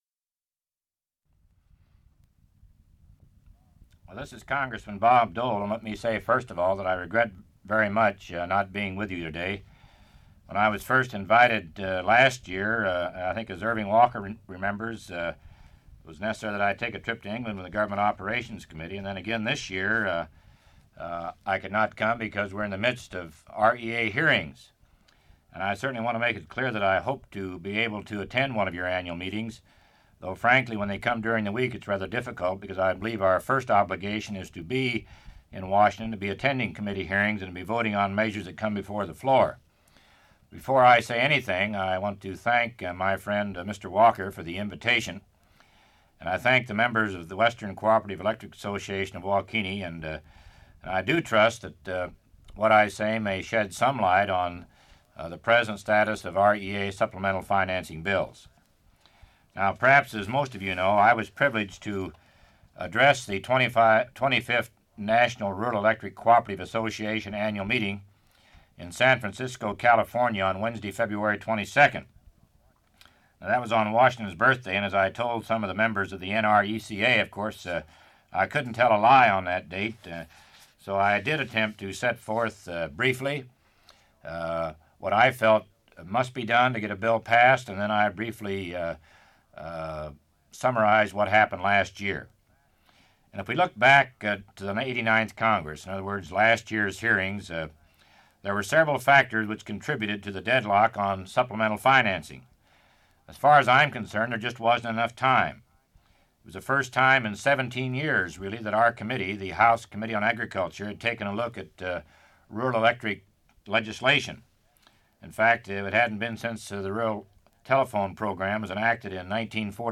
Part of Speech by Representative Dole to the Kansas Electric Cooperative